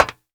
METAL 1D.WAV